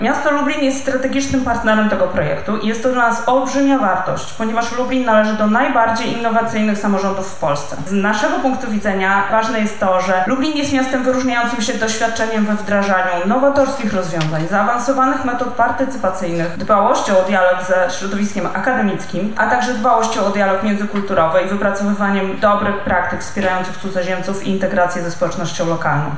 Na Wydziale Filozofii i Socjologii UMCS spotkali się przedstawiciele administracji publicznej, środowiska eksperckiego oraz sektora społecznego w obszarze zarządzania migracją.